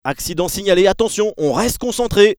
Waze vous offre les voix des nouveaux commentateurs du jeu FIFA 23 (Omar da Fonseca et Benjamin Da Silva)
Dans votre voiture, équipé de l’application de navigation, vous pourrez donc vivre l’ambiance d’un match de foot au volant en installant les voix du duo de beIN SPORTS.